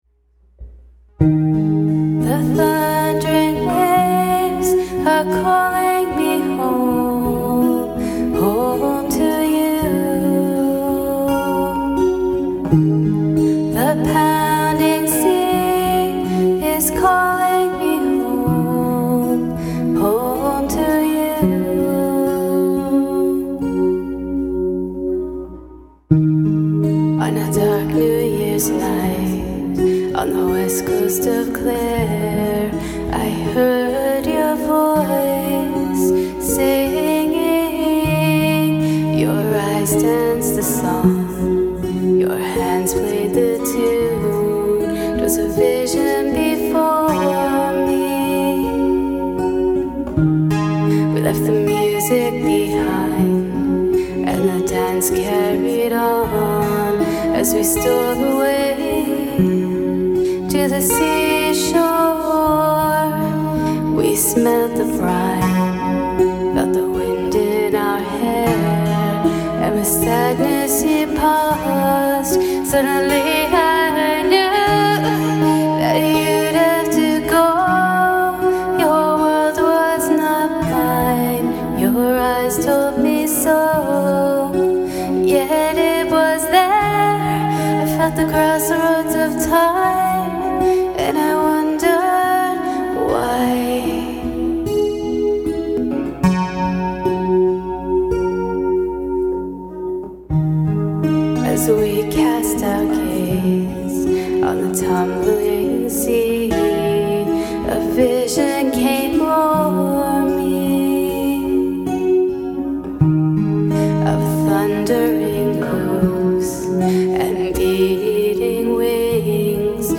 my guitar playing on this isn't too good